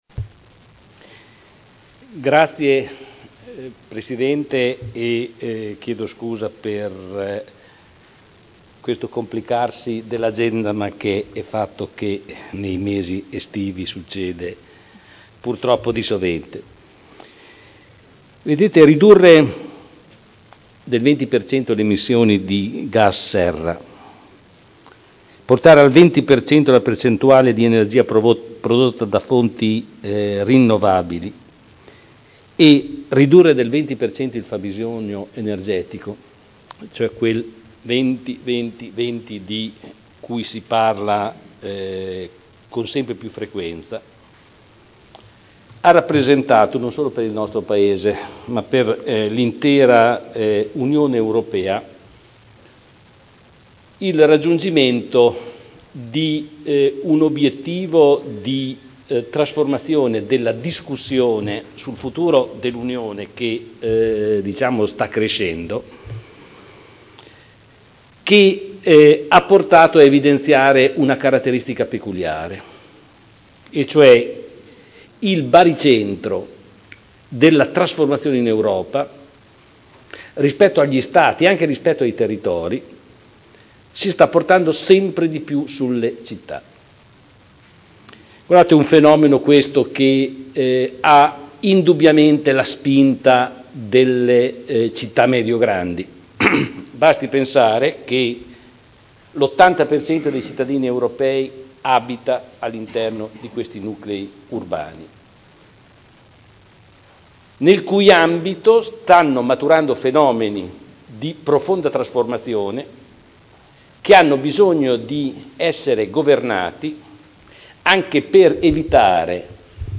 Seduta del 18/07/2011. Piano d’Azione per l’Energia Sostenibile (SEAP)